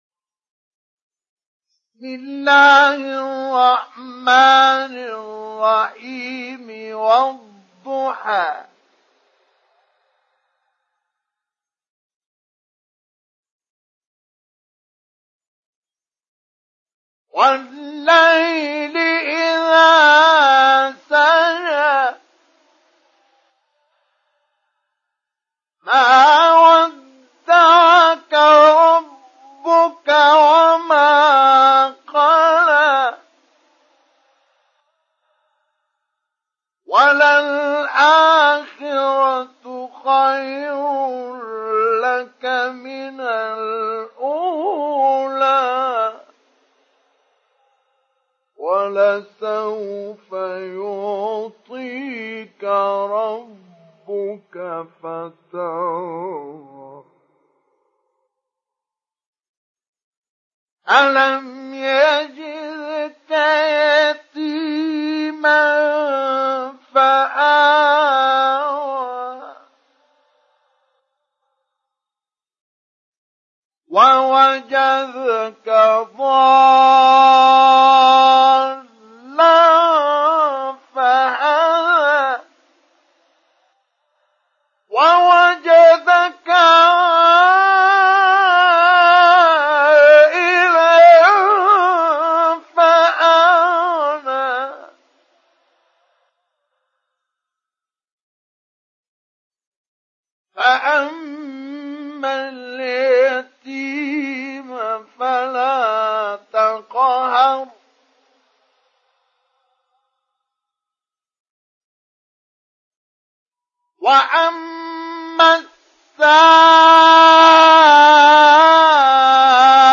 تحميل سورة الضحى mp3 بصوت مصطفى إسماعيل مجود برواية حفص عن عاصم, تحميل استماع القرآن الكريم على الجوال mp3 كاملا بروابط مباشرة وسريعة
تحميل سورة الضحى مصطفى إسماعيل مجود